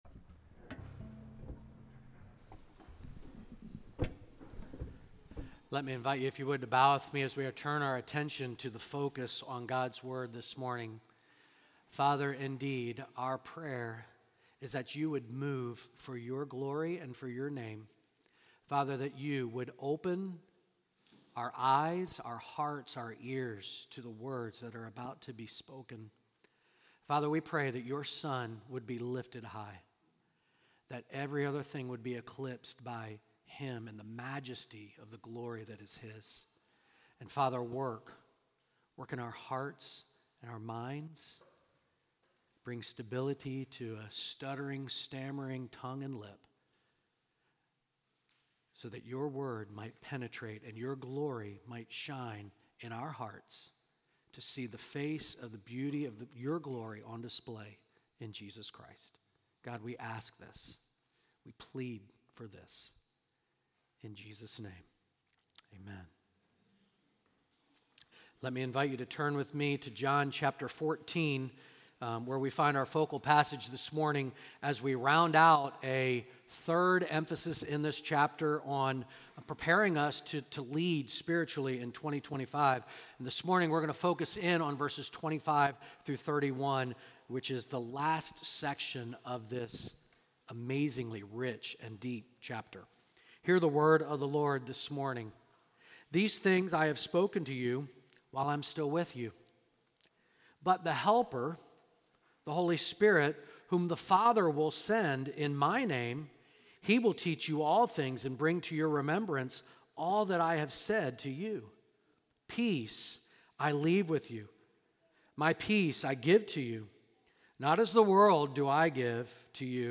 Sermons | Great Commission Baptist Church